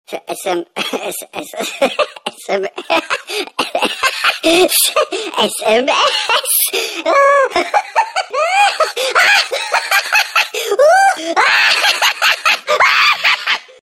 File Type : Mp3 ringtones